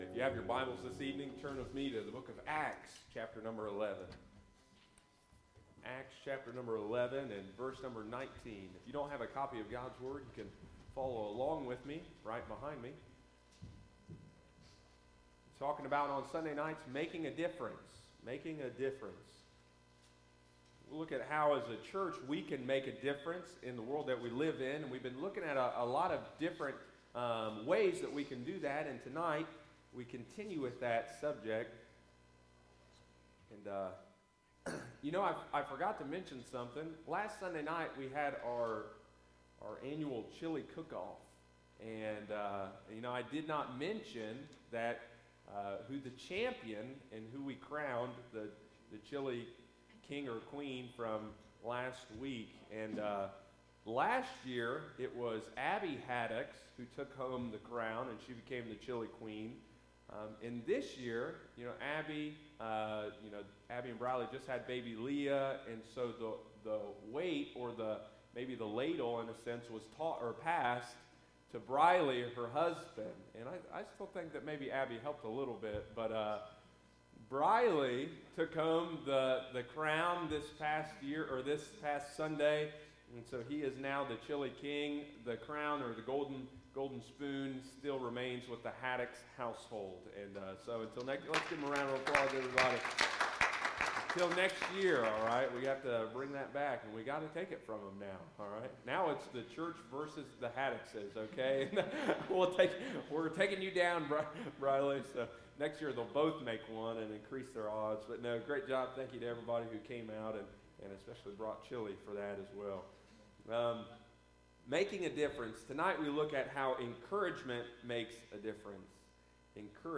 continues the Sunday evening study through the book of Acts on February 25.